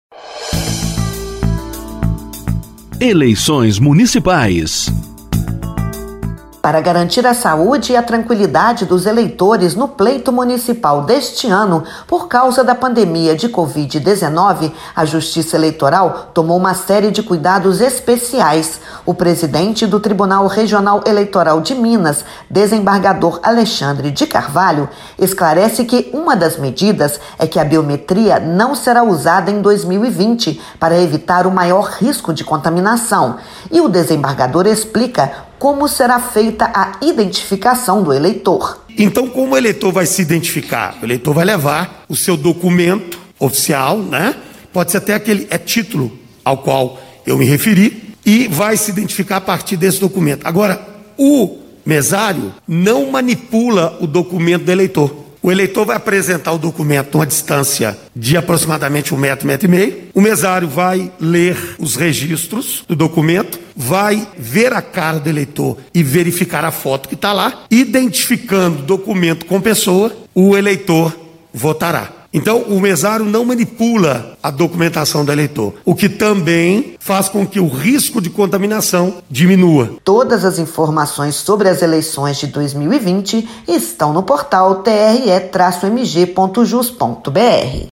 Ouça a explicação do presidente do Tribunal Regional Eleitoral em Minas, desembargador Alexandre de Carvalho.